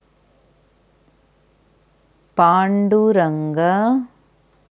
Dieses Wort auf Devanagari schreibt man पान्दुरन्ग, in der IAST Transliteration mit diakritischen Zeichen pānduranga. Hier hörst du, wie eine Sanskrit Expertin das Wort Panduranga ausspricht.